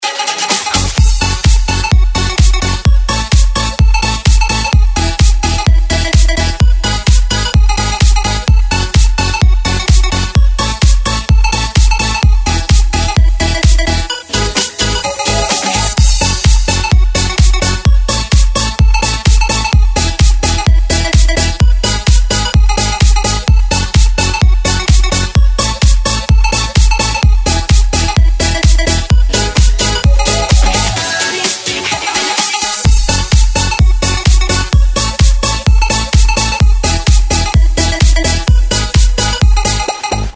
Клубные [95]